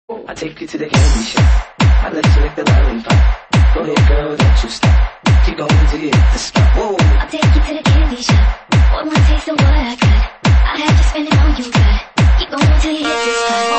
• Dance Ringtones